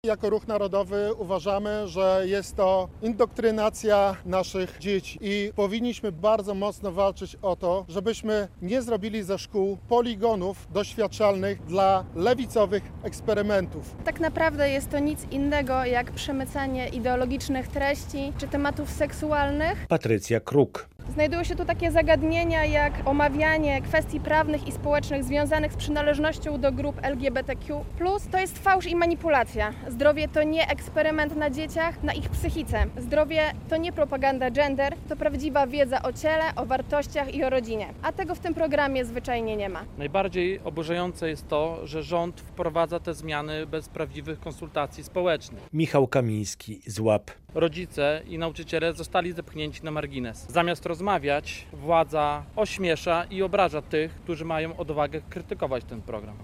Wszyscy uczestnicy konferencji prasowej na Starym Rynku w Łomży zgodnie namawiali rodziców by - w imieniu swoich dzieci - rezygnowali z ich uczęszczania na lekcje edukacji zdrowotnej.